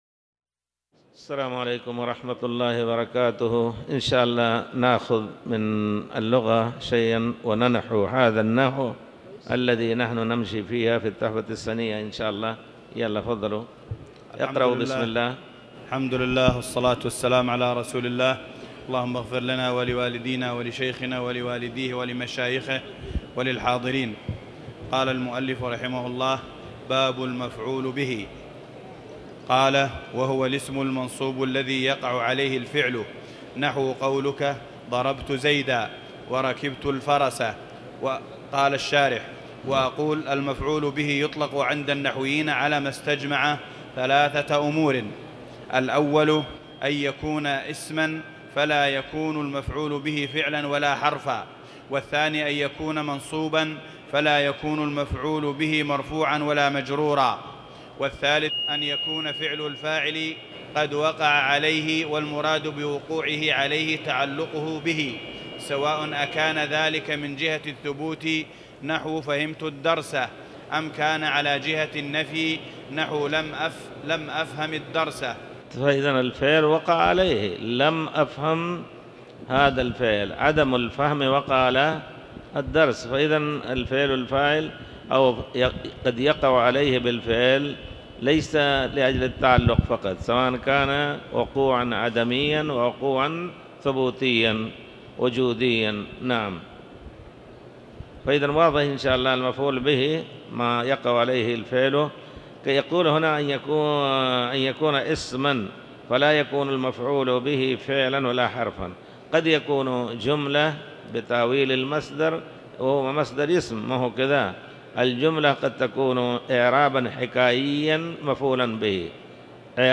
تاريخ النشر ٦ جمادى الأولى ١٤٤٠ هـ المكان: المسجد الحرام الشيخ